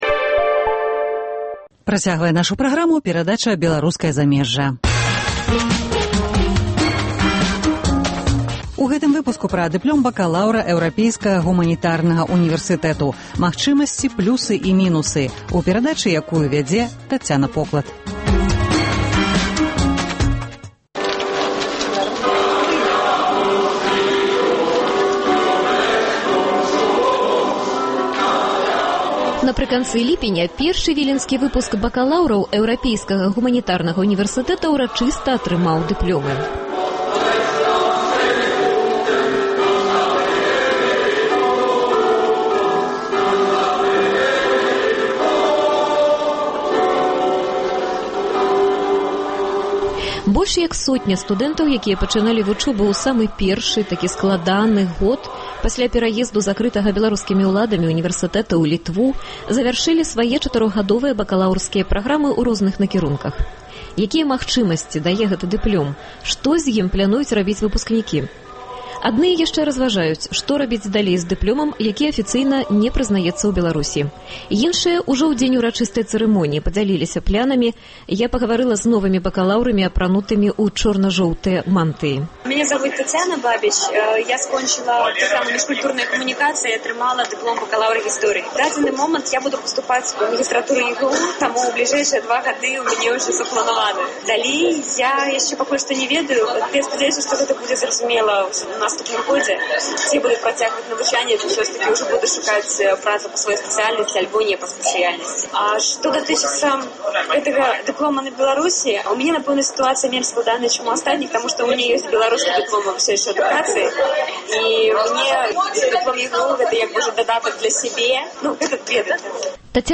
Першы выпуск бакаляўраў, якія пачыналі вучобу у Вільні, атрымаў дыплёмы Эўрапейскага гуманітарнага унівэрсытэту, што цягам апошніх чатырох гадоў працуе у Літве як беларускі унівэрсытэт у выгнаньні. Дыплём бакаляўра ЭГУ – пра яго магчымасьці, плюсы і мінусы – у новай перадачы Беларускае замежжа з удзелам выпускнікоў, выкладчыкаў і новых студэнтаў, прыём якіх адбываецца акурат цяпер.